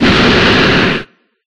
Fire6.ogg